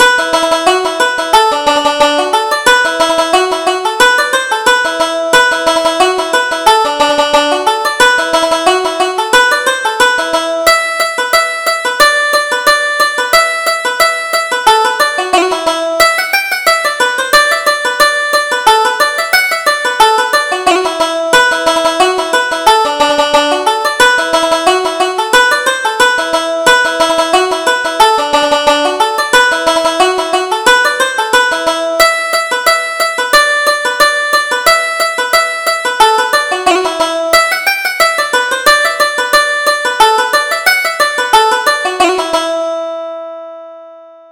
Reel: The Pretty Blue Seagull